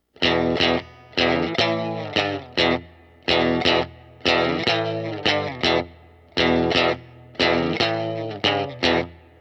Standardbluesrhythmus.